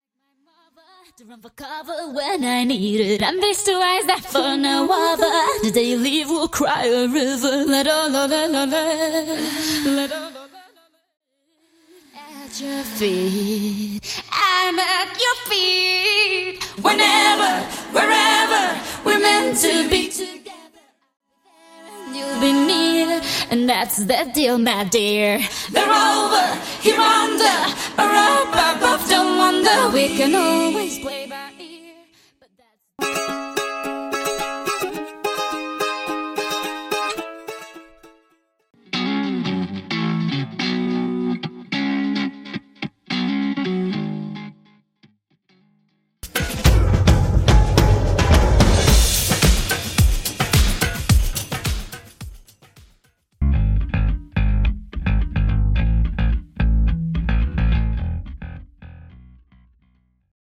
Studio Bassline Guitar Stem
Studio Electric Guitar Stem
Studio Percussion & Drums Stem
Studio Strings & Guitar Stem